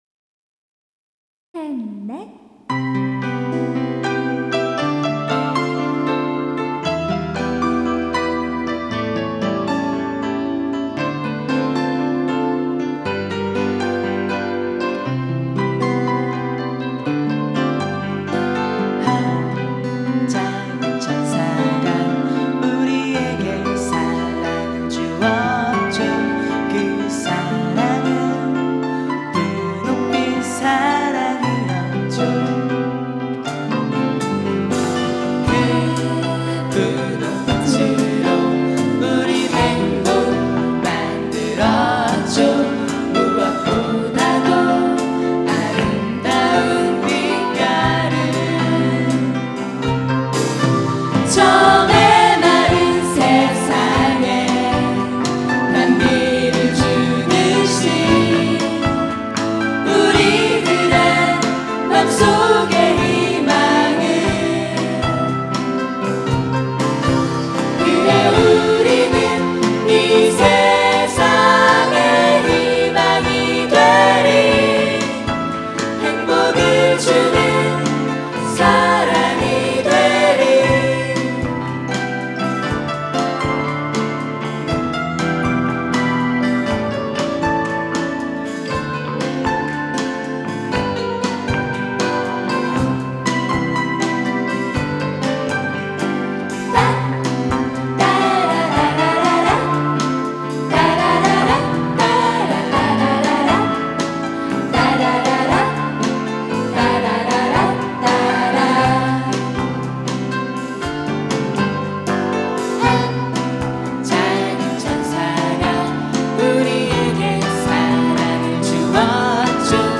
2004년 제 24회 정기 대공연
홍익대학교 신축강당
노래
어쿠스틱 기타
신디사이저